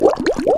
Sfx Boat Through Water Sound Effect
Download a high-quality sfx boat through water sound effect.
sfx-boat-through-water-4.mp3